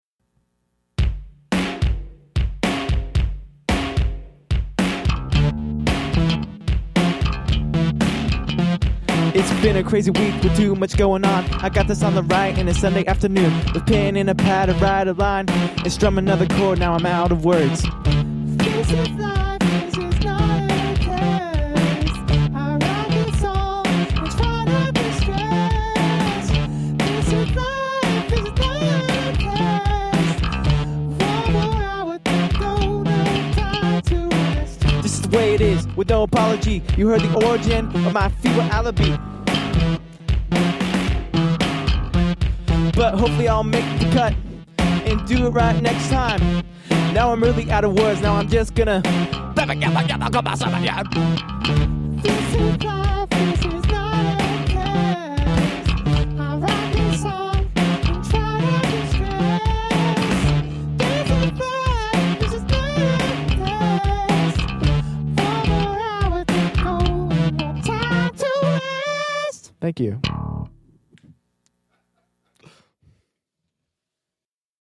Cool bass.
I am glad to see your production has improved from last year; I faintly remember some of your songs from back then to suffer from digital clipping or something like that.